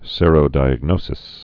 (sîrō-dīəg-nōsĭs)